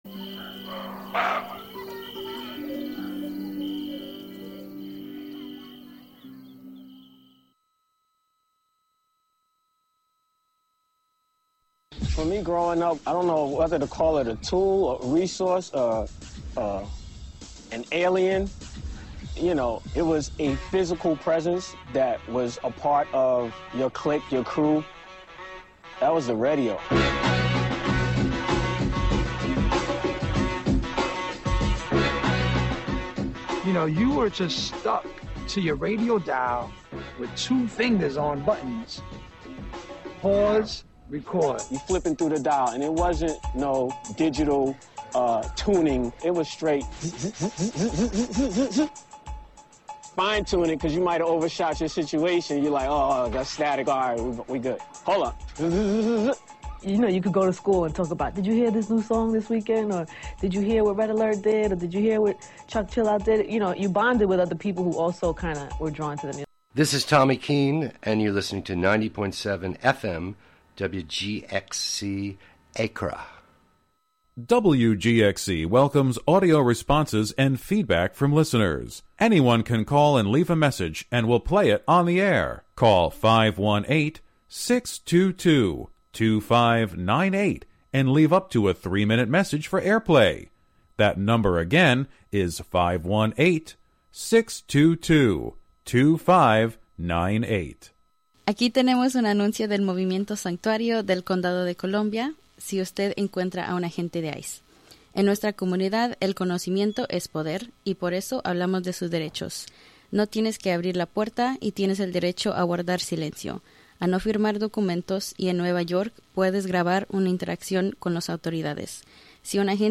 Each show, "The Radio Museum" broadcasts a collection of different voices from radio's past. True raconteurs who knew how to tell intimate, personal, engaging stories on the radio.
Plus some music from the era as well as a weekly recipe.